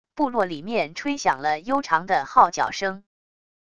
部落里面吹响了悠长的号角声wav音频